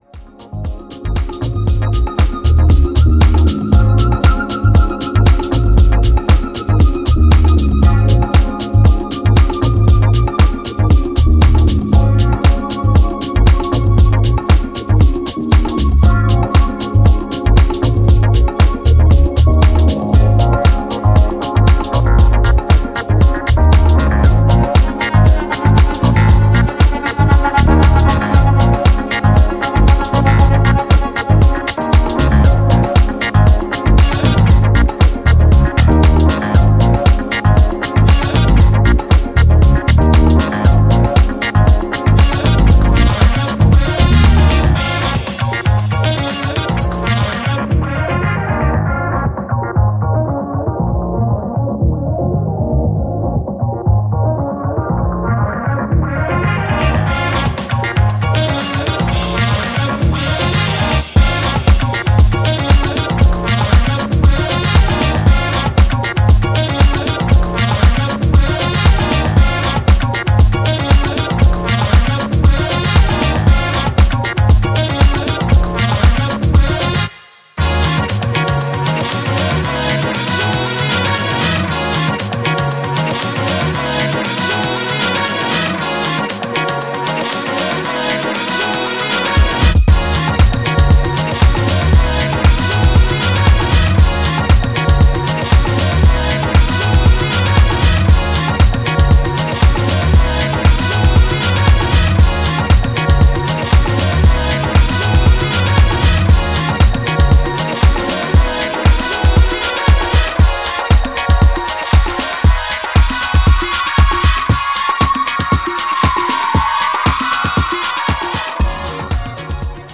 【12"INCH】(レコード)
ジャンル(スタイル) DEEP HOUSE / HOUSE